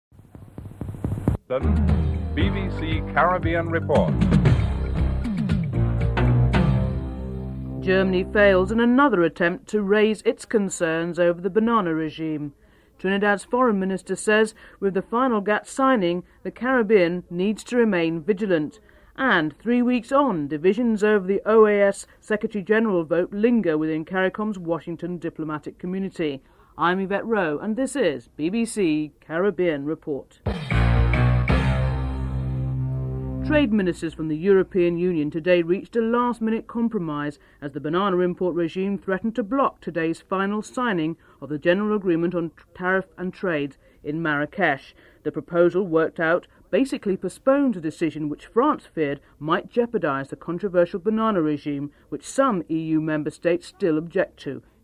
7. Wrap up & theme music (14:42-1501)